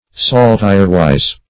Search Result for " saltirewise" : The Collaborative International Dictionary of English v.0.48: Saltirewise \Sal"tire*wise`\, adv.
saltirewise.mp3